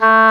WND D OBOE07.wav